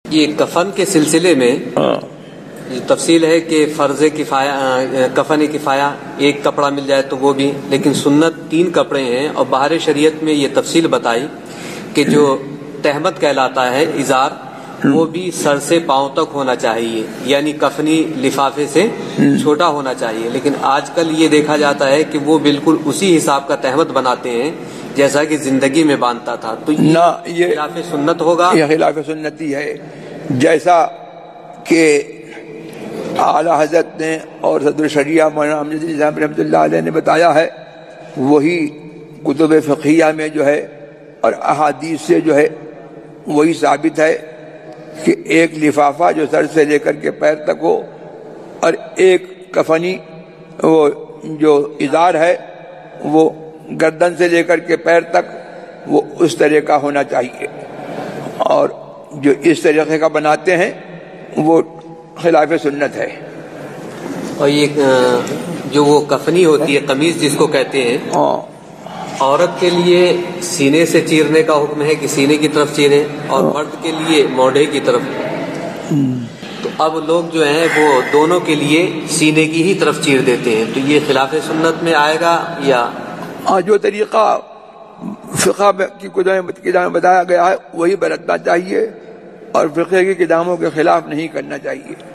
Answer (Voice Recording):